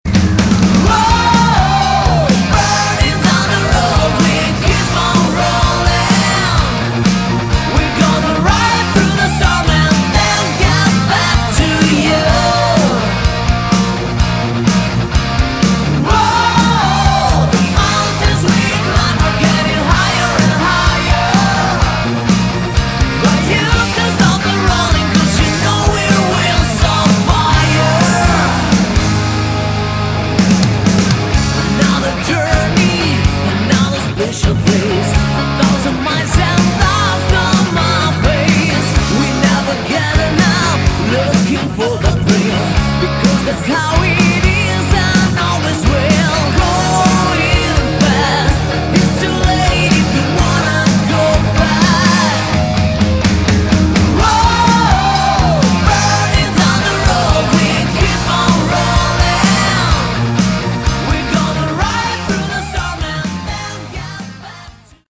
Category: Melodic Rock
vocals
guitars
keyboards
bass
drums